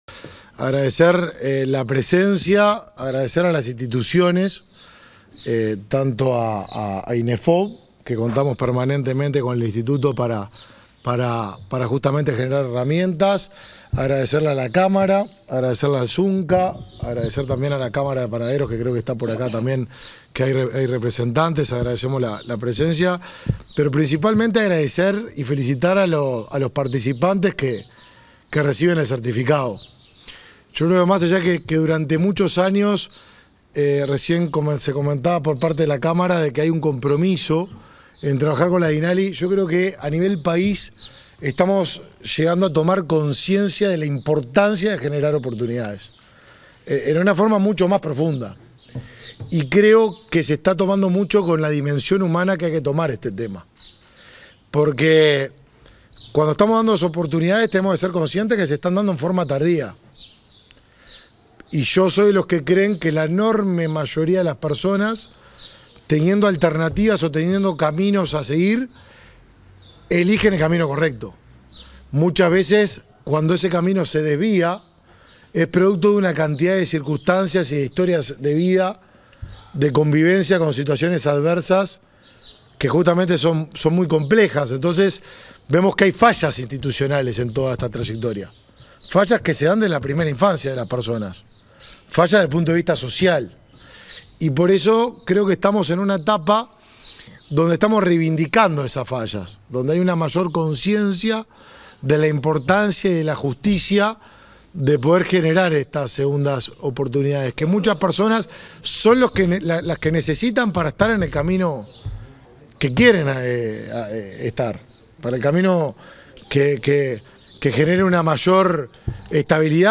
Palabras del ministro de Desarrollo Social y del director de Inefop
En el marco del convenio y entrega de diplomas a participantes de la Dirección Nacional de Apoyo al Liberado (Dinali) en Montevideo y zona metropolitana que finalizaron la capacitación, este 27 de octubre, se expresaron el ministro de Desarrollo Social, Martín Lema, y el director del Instituto Nacional de Empleo y Formación Profesional (Inefop), Pablo Darscht.